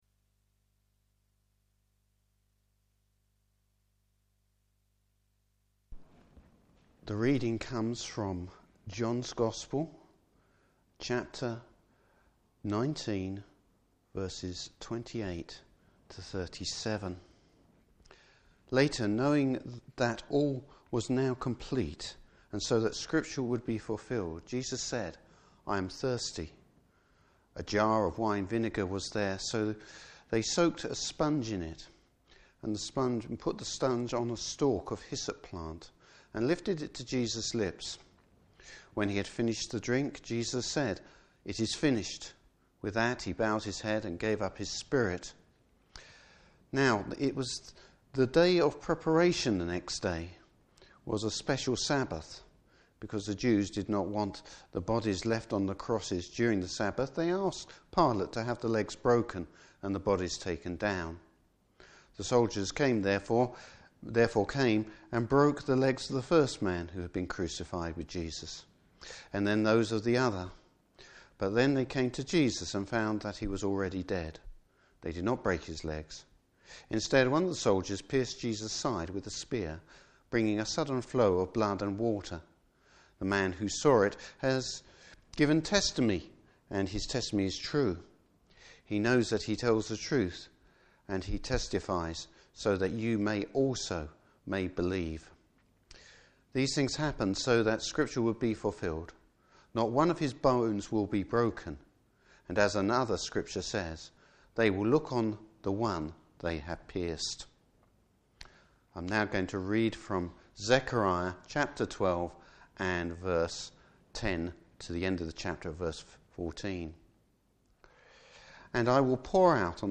Service Type: Good Friday Service.